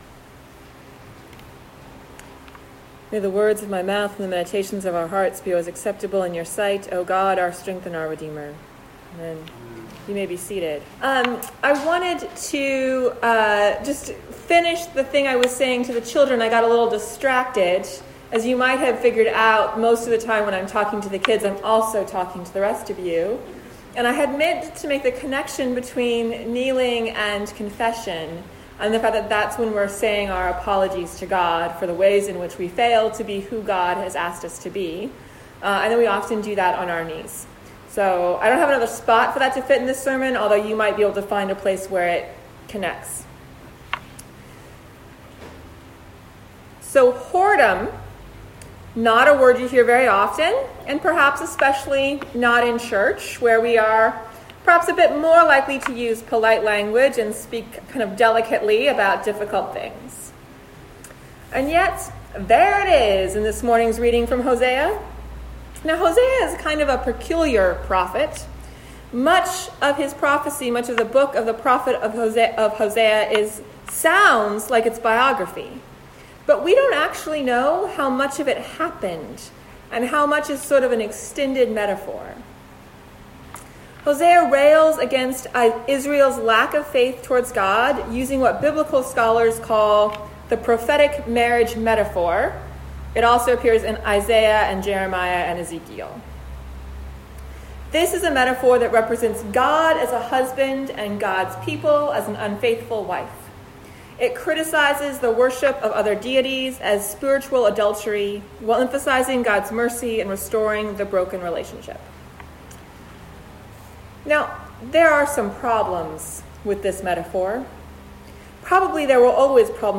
Sermons | St. Stephen the Martyr Anglican Church